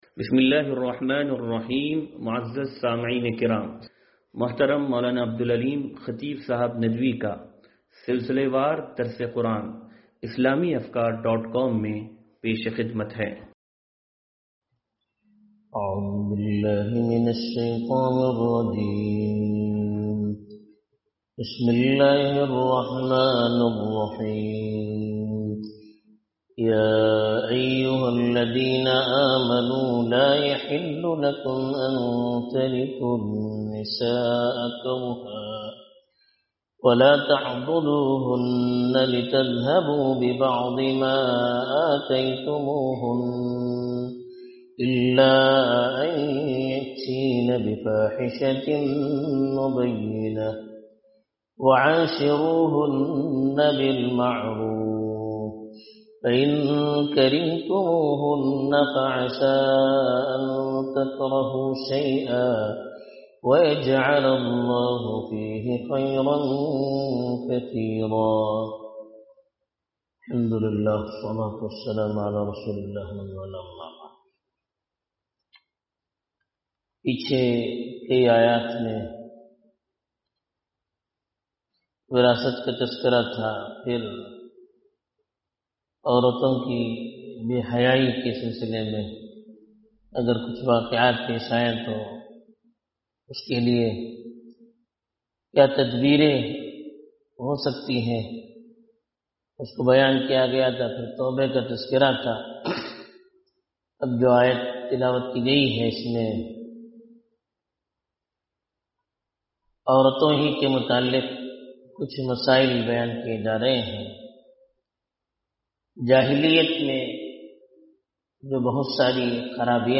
درس قرآن نمبر 0335
درس-قرآن-نمبر-0335-2.mp3